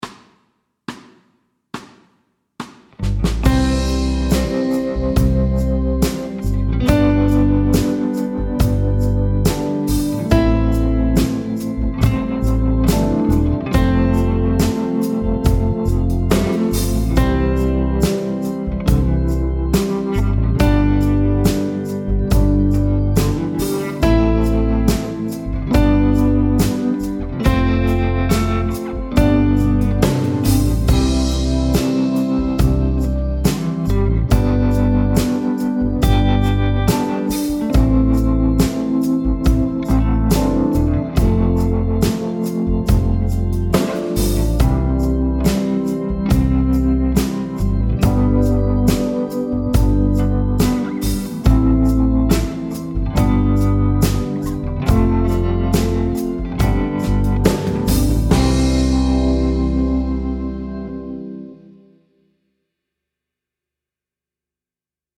Medium C instr (demo)
I dette afsnit skal du læse og spille 3 toner: C, D og E.
Rytmeværdier: 1/1- og 1/2 noder.